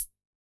RDM_Raw_SR88-ClHat.wav